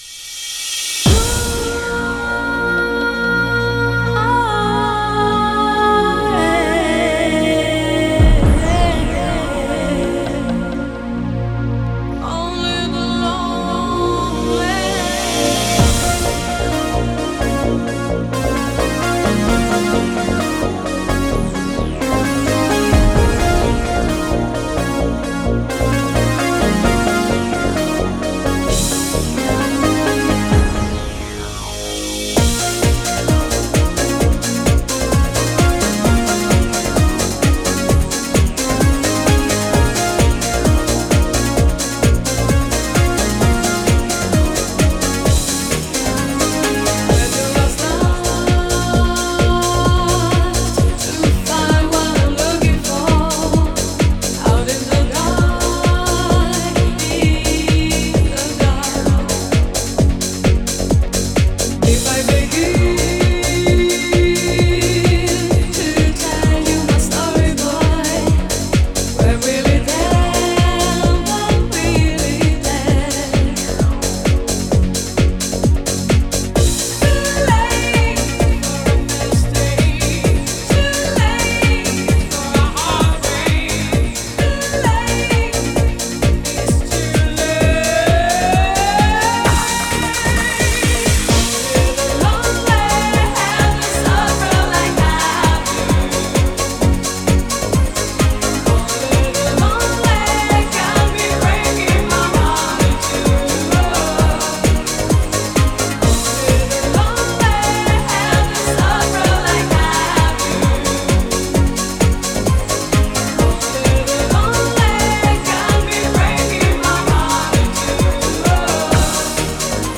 Genre: Italodance.